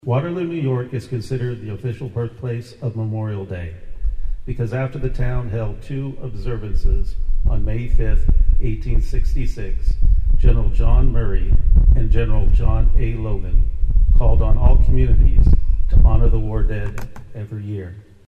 American Legion Post 17 hosted its annual Memorial Day ceremony in Sunset Cemetery on Monday, honoring those who had died in the fight for freedom.
Manhattan city commissioner John Matta was the keynote speaker at Monday’s ceremony.